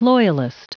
Prononciation du mot loyalist en anglais (fichier audio)
Prononciation du mot : loyalist